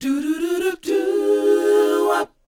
DOWOP A#4A.wav